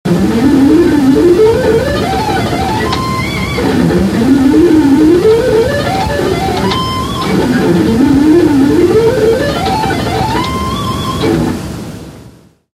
SHRED PICKING: Alternate & Sweep Picking
The MP3s of the two fast-picking lines I'm presenting below were taken from one of a zillion old tapes I have of me trying to blaze...
The audio quality is pretty bad, but at least you can hear the stuff.
Alternate Picking Lick
ShredPicking(ALT)1995.mp3